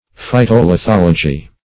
Search Result for " phytolithology" : The Collaborative International Dictionary of English v.0.48: Phytolithology \Phy`to*li*thol"o*gy\, n. [Phyto- + lithology.]